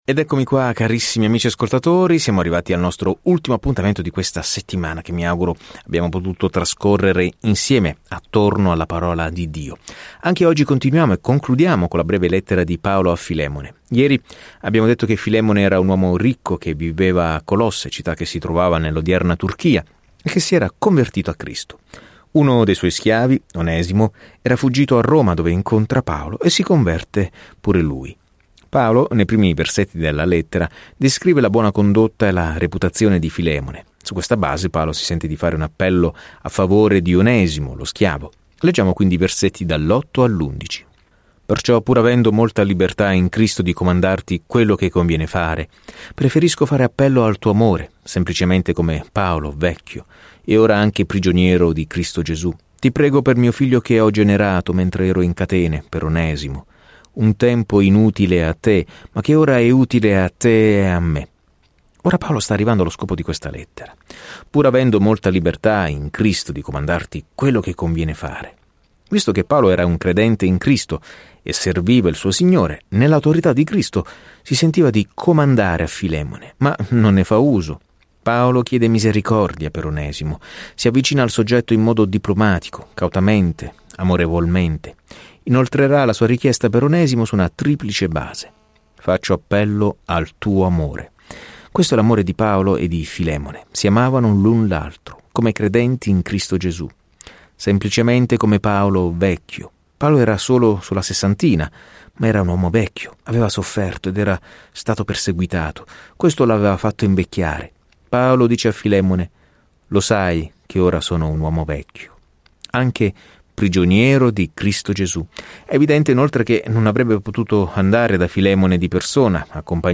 Scrittura Lettera a Filemone 1:8-25 Giorno 1 Inizia questo Piano Giorno 3 Riguardo questo Piano Il Vangelo cambia la vita, proprio come questa breve lettera esorta un fratello a accogliere un altro fratello. Viaggia ogni giorno attraverso Filemone mentre ascolti lo studio audio e leggi versetti selezionati della parola di Dio.